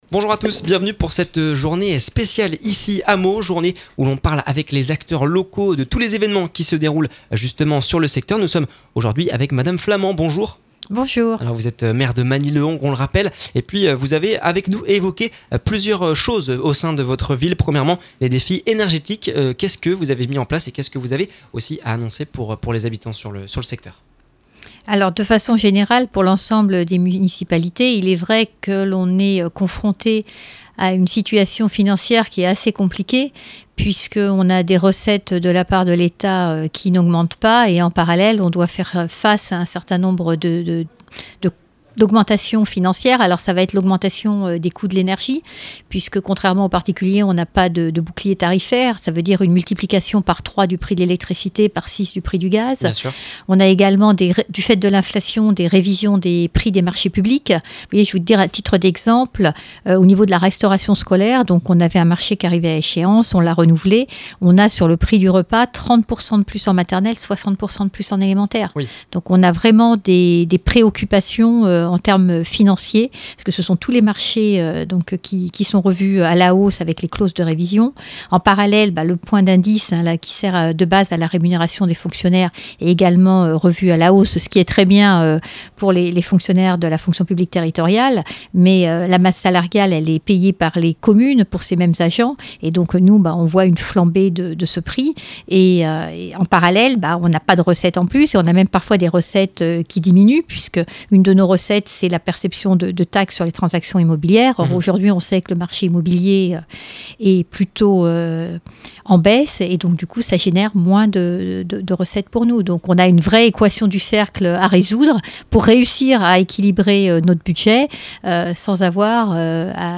Les collectivités et acteurs locaux du département se sont donnés rendez-vous dans les locaux de Meaux pour une journée spéciale sur Oxygène. L’occasion pour Véronique Flament, maire de Magny-le-Hongre d’évoquer les sujets majeurs de la commune.